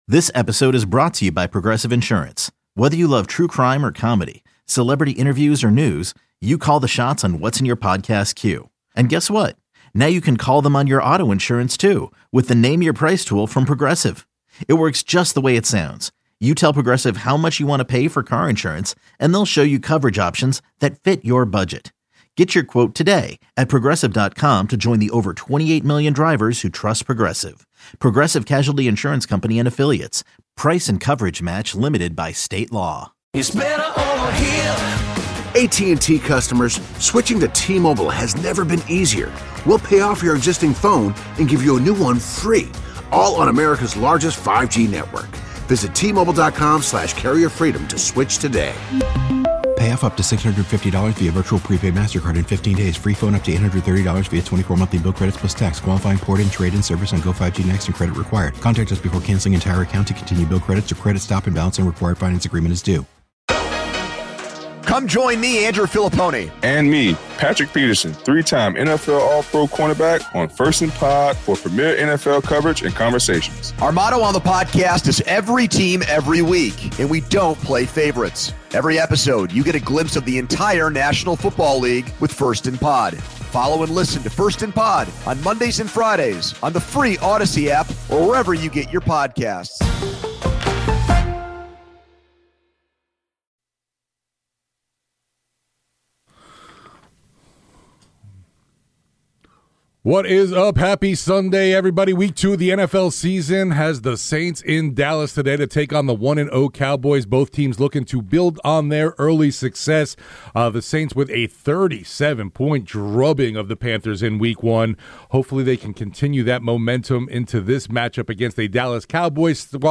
Saints Interviews